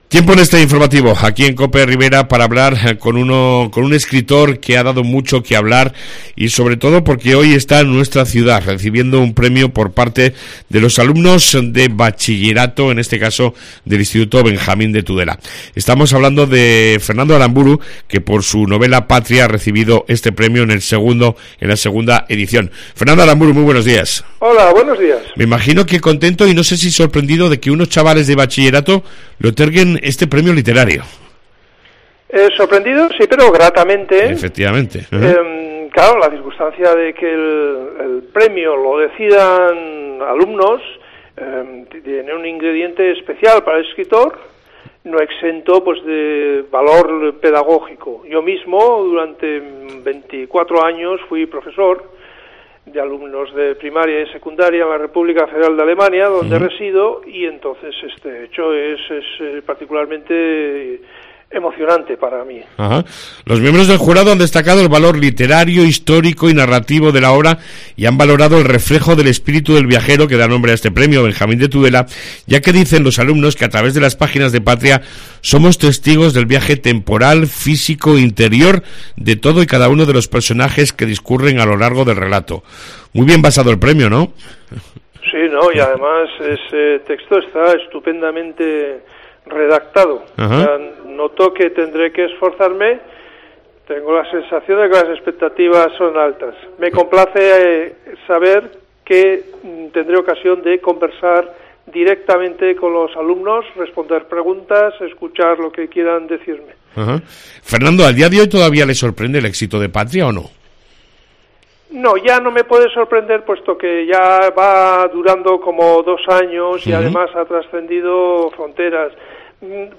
Entrevista con el escritor Fernando Aramburu